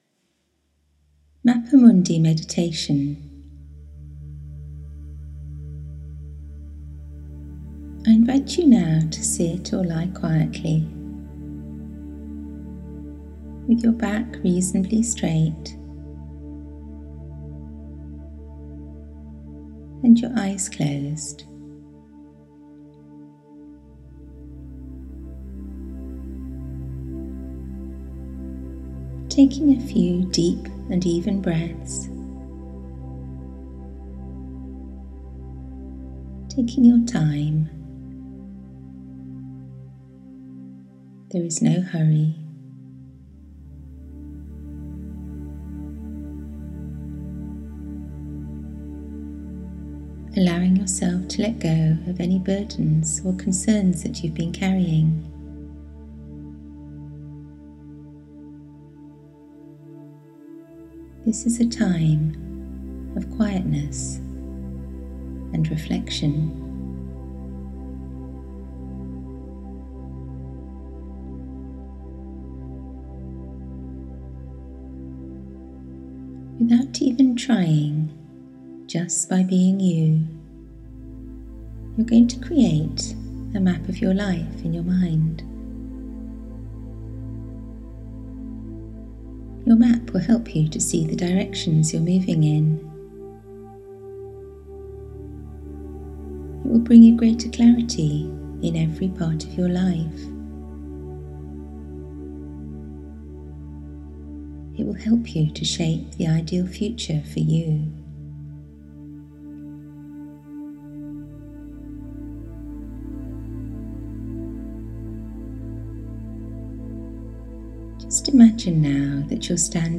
Mappa mundi – a (free) audio meditation
mappa-mundi-meditation.m4a